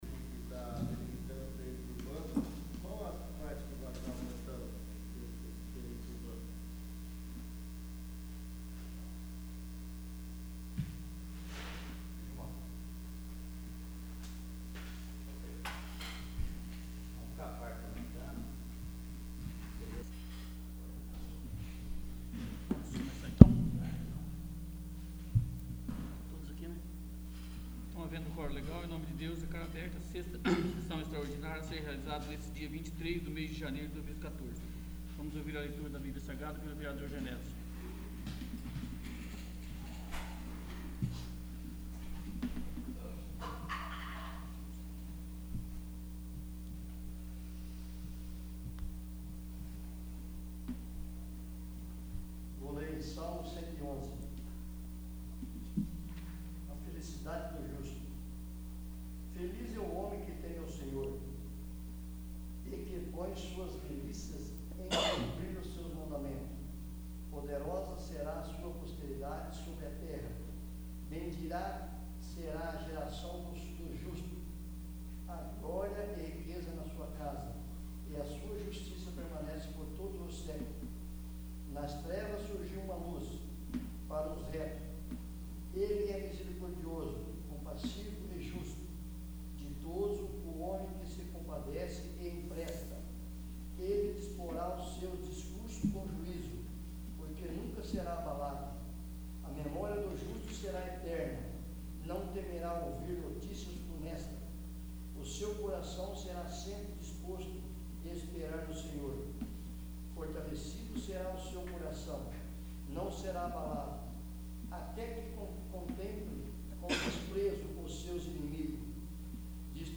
6º. Sessão Extraordinária